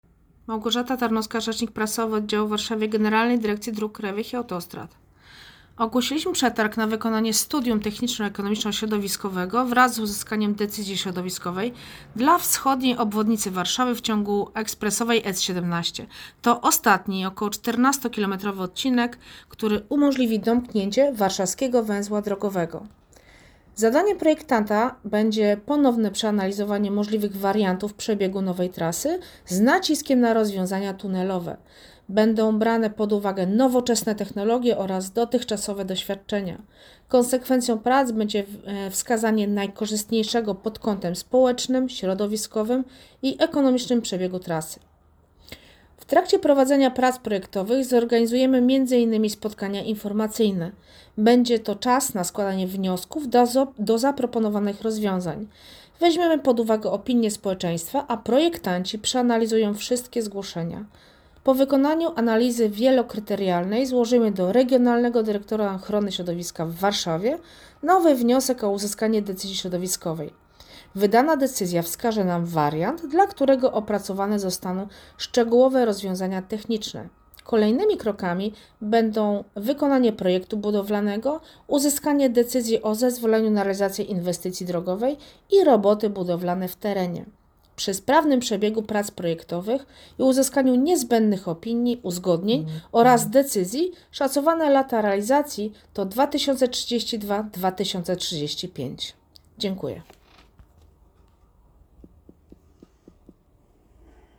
Wypowiedź rzecznika - Szukamy projektanta, który wytyczy przebieg S17 WOW